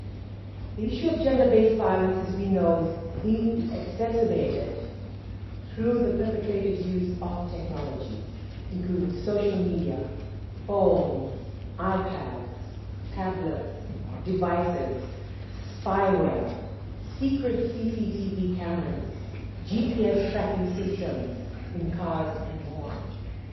While speaking at the opening of the Pacific Cyber Safety Symposium in Suva today, Minister for Women and Children Lynda Tabuya says global data reveals that the global prevalence of online violence against women and girls is staggering at 85 percent.